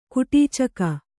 ♪ kuṭīcaka